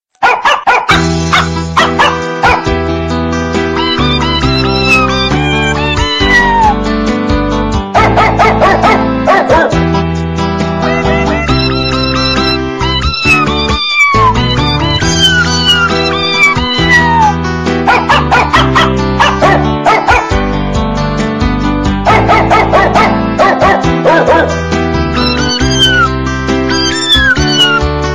• Качество: 128, Stereo
мяуканье
лай собаки
Прикольный рингтончик с лаем и мяуканьем